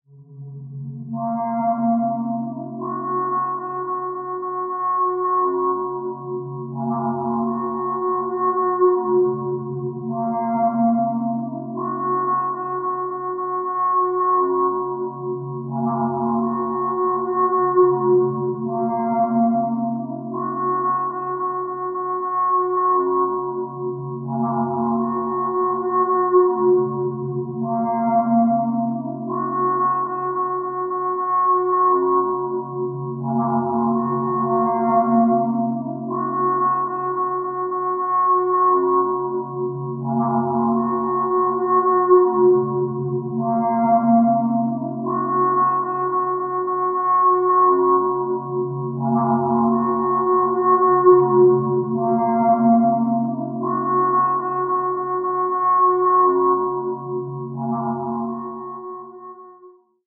Здесь вы найдете как абстрактные шумы, так и более структурированные аудиоиллюзии.
Звук искажения действительности